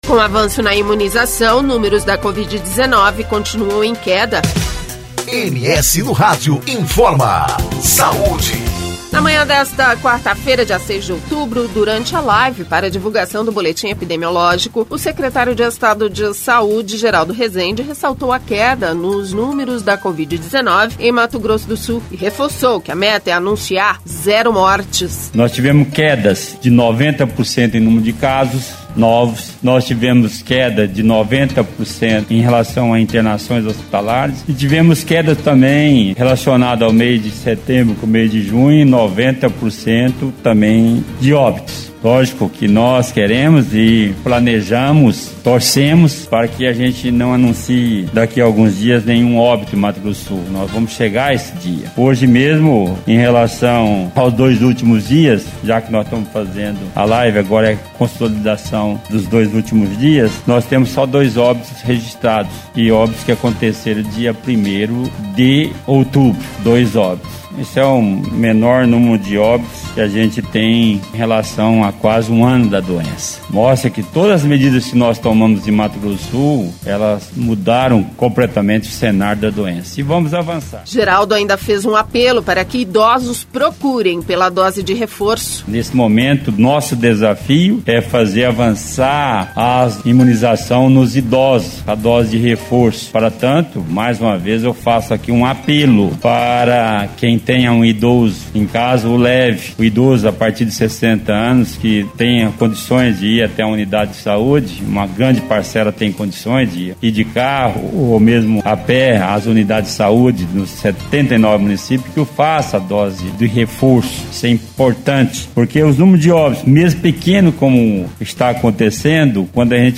Na manhã desta quarta-feira, dia 06, durante a live para divulgação do boletim epidemiológico, secretário de estado de saúde, Geraldo Resende, ressaltou a queda nos números da Covid-19 em Mato Grosso do Sul, e reforçou que a meta é anunciar zero mortes.